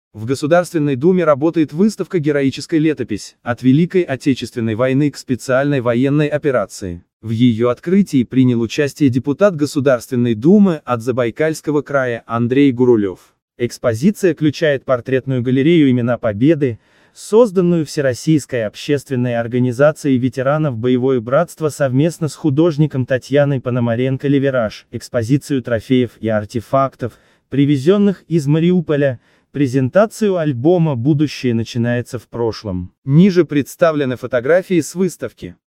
Нет времени читать новость прослушай её электронную версию